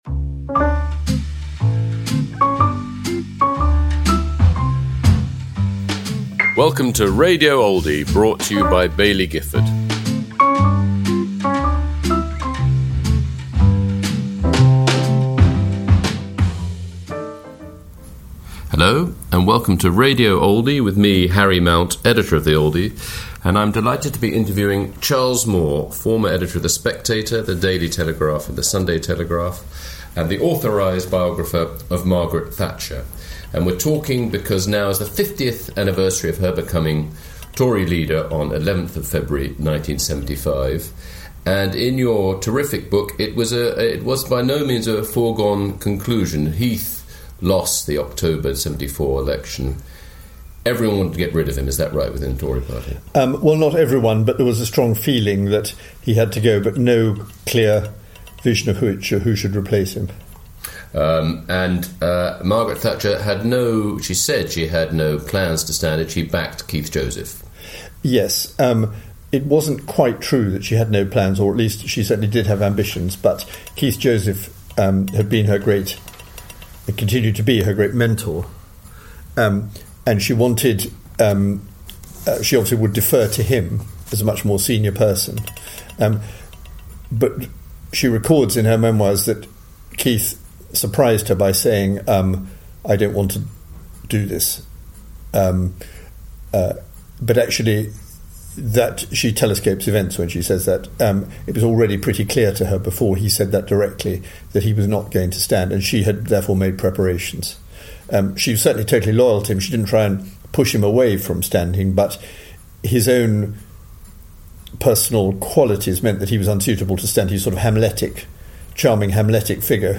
The Oldie magazine’s podcast featuring discussion and debate around the lead features in the latest magazine, plus live recordings from our famous Literary Lunches.